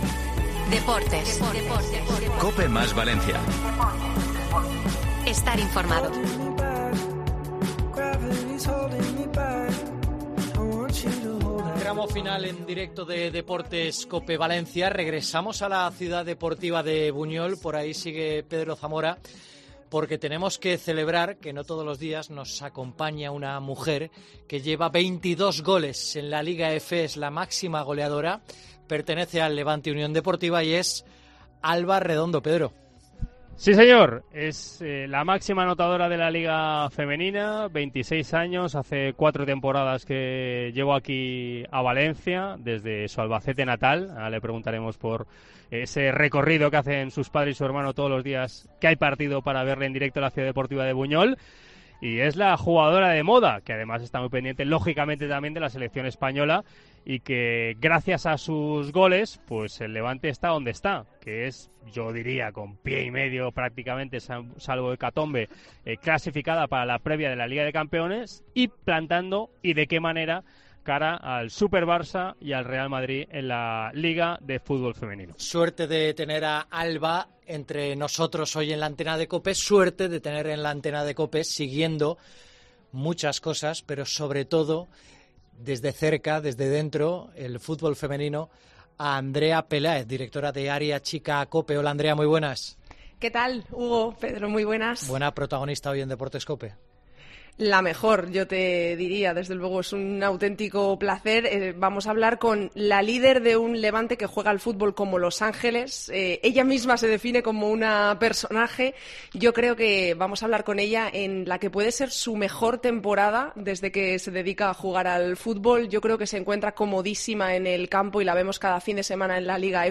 Entrevista a Alba Redondo en Deportes COPE Valencia